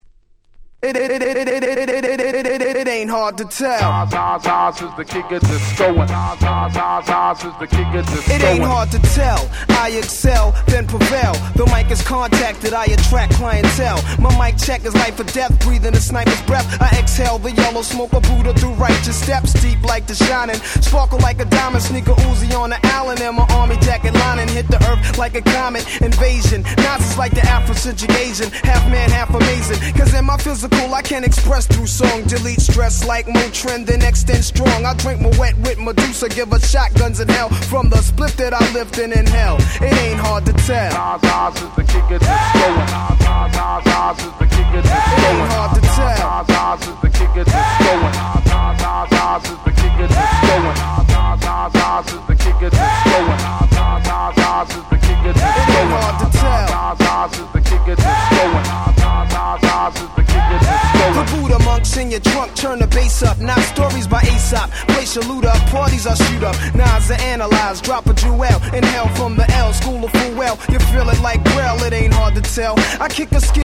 99' Super Hit Hip Hop !!
Boom Bap